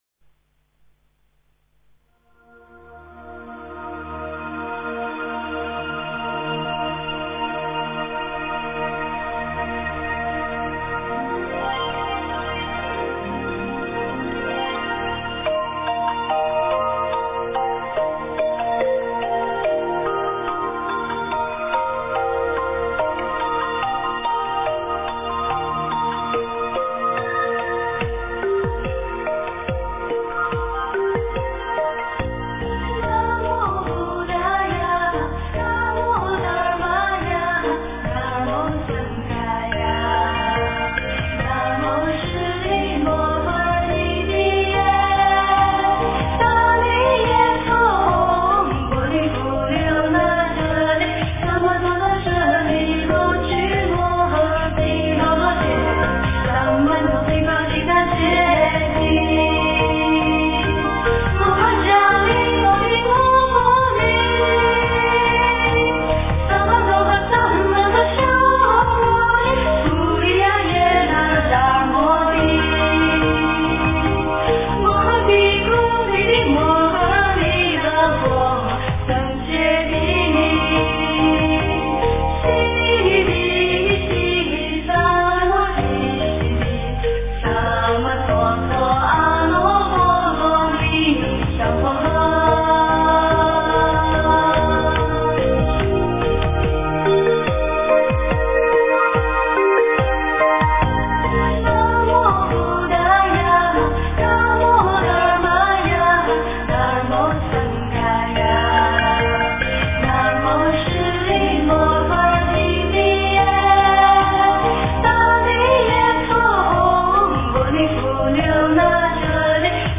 佛音 诵经 佛教音乐 返回列表 上一篇： 般若心咒 下一篇： 药师佛心咒 相关文章 般若波罗蜜多心经(台语